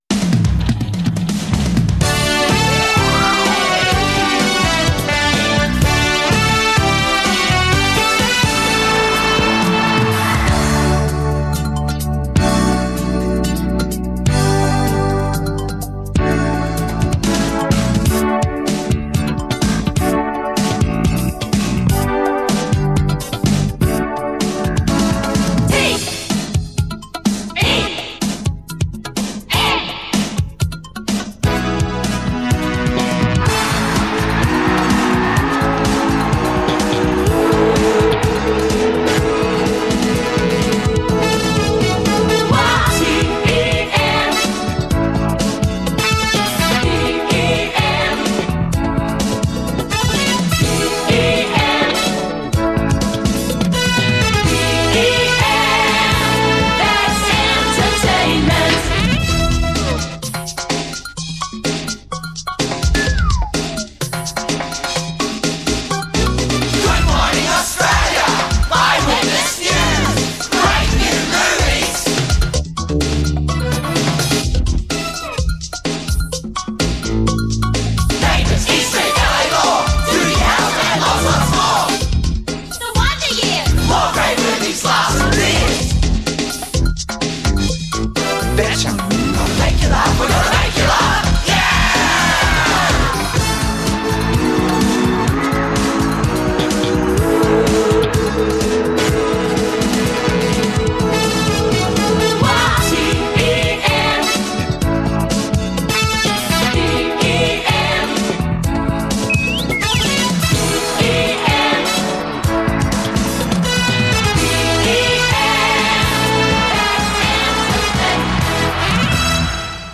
AI vocal removal